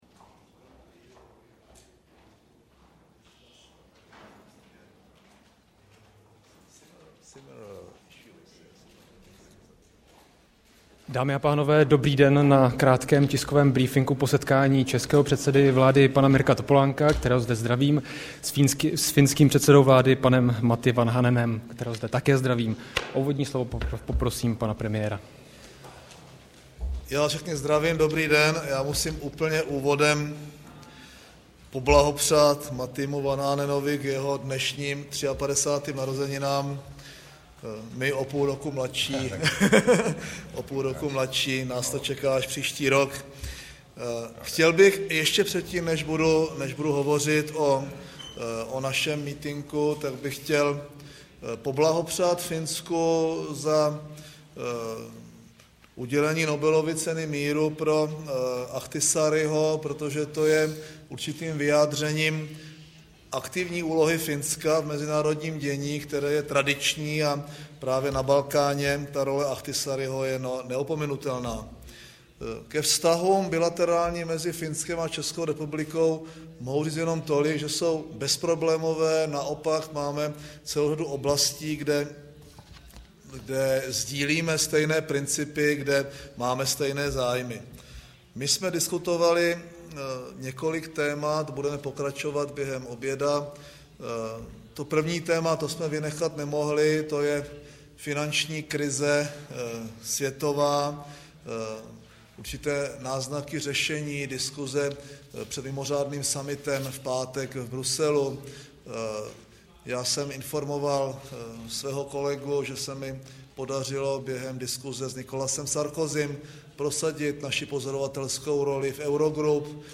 Premiér v úvodu tiskové konference blahopřál Finsku k získání Nobelovy ceny míru, jejímž držitelem se za rok 2008 stal finský diplomat, bývalý finský prezident, později zvláštní zmocněnec OSN pro Kosovo Martti Oiva Kalevi Ahtisaari.